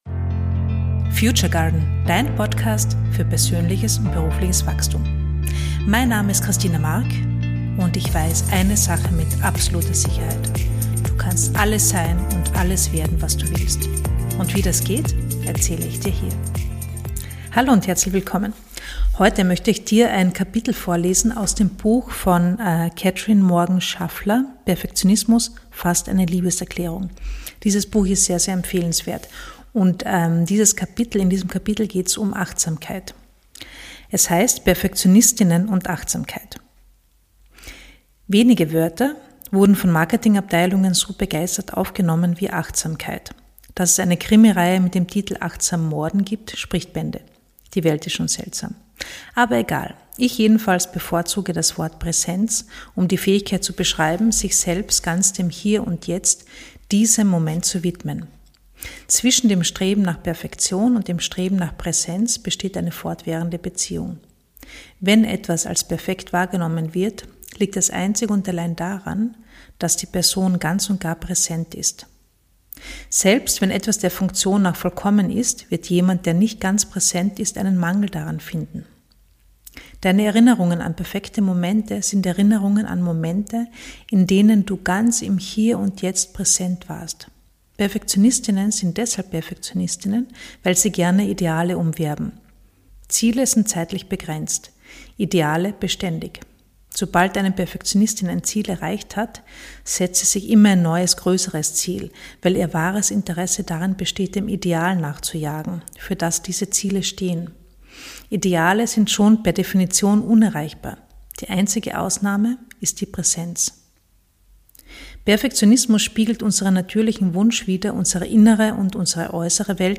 Sie wird dich vielleicht mit deinem Perfektionismus aussöhnen oder zumindest neugierig darauf machen, was es hier zu entdecken gibt. Das Buch aus dem ich vorlese heißt: Perfektionismus (fast) eine Liebeserklärung und stammt von Katherine Morgan Schafler.